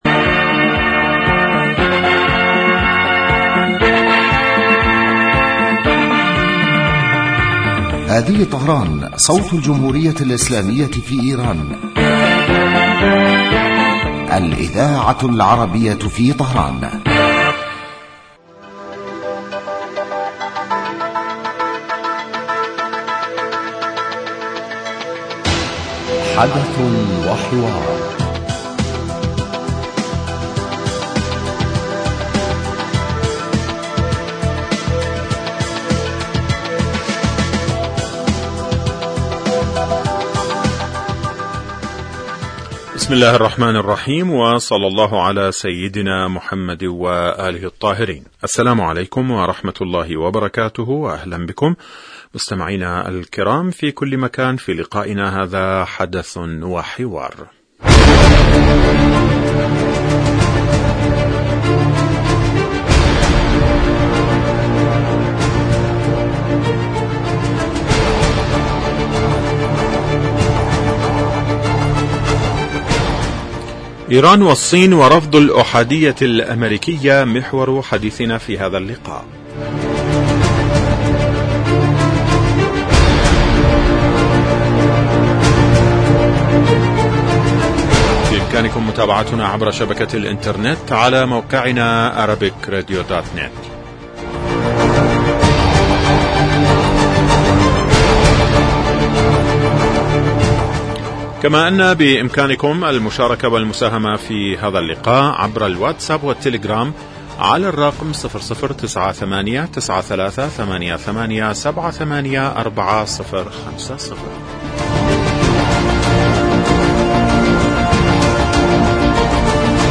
يبدأ البرنامج بمقدمة يتناول فيها المقدم الموضوع ثم يطرحه للنقاش من خلال تساؤلات يوجهها للخبير السياسي الضيف في الاستوديو . ثم يتم تلقي مداخلات من المستمعين هاتفيا حول الرؤى التي يطرحها ضيف الاستوديو وخبير آخر يتم استقباله عبر الهاتف ويتناول الموضوع بصورة تحليلية.